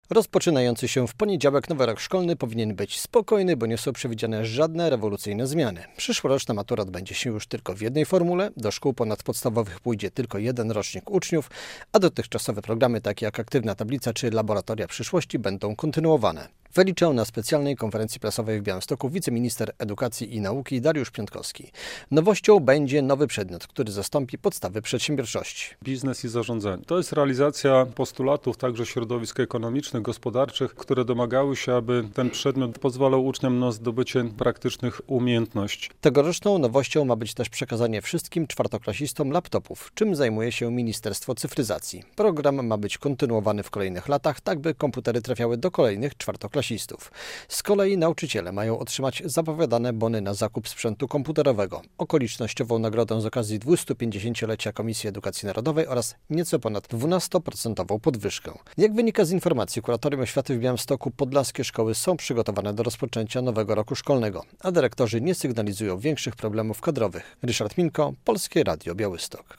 Bez rewolucji, raczej z kontynuacją dotychczasowych programów - tak ma wyglądać nadchodzący nowy rok szkolny. Czego mogą się spodziewać nauczyciele i uczniowie - o tym na specjalnej konferencji prasowej w Białymstoku mówił wiceminister Edukacji i Nauki Dariusz Piontkowski.
Nowy rok szkolny powinien być spokojny - zapowiada wiceminister Dariusz Piontkowski - relacja